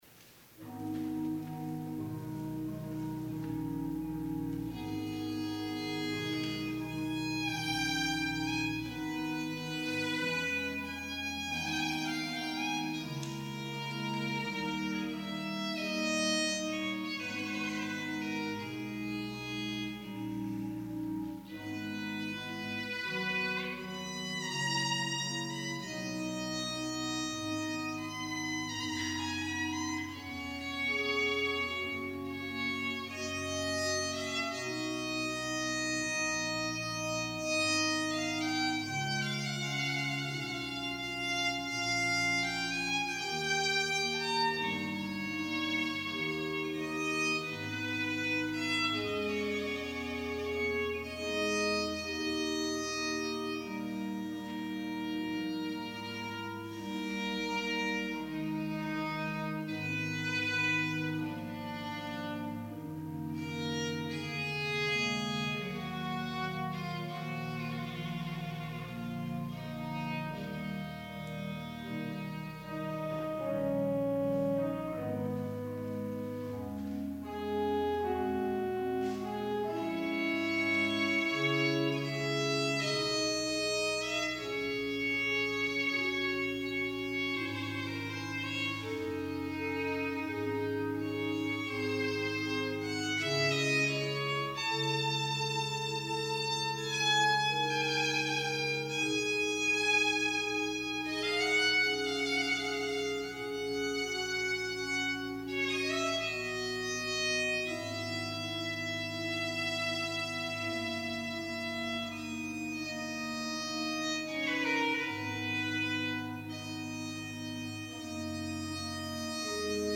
VOLUNTARY Larghetto George Frederick Handel
violin
organ